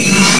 RazorHum.ogg